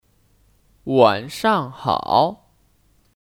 晚上好 Wǎnshang hǎo : Selamat malam